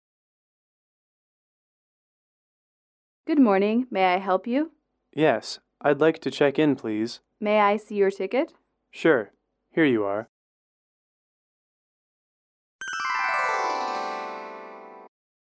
英语口语情景短对话16-1：登机检票(MP3)